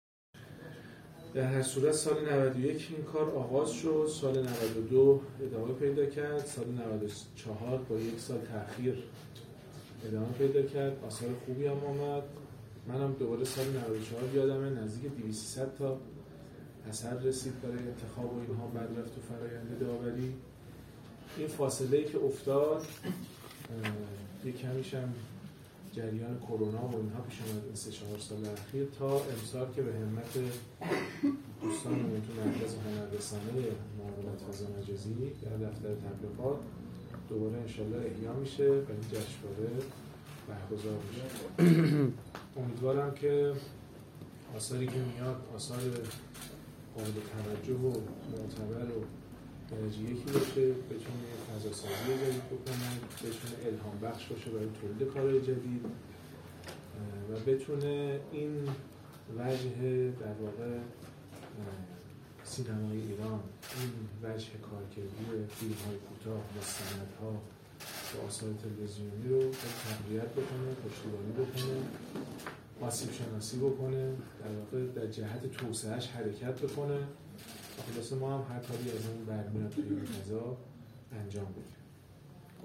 نشست خبری چهارمین جشنواره فیلم حوزه (اشراق)